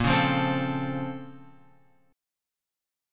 A#mM9 chord {6 4 3 5 x 5} chord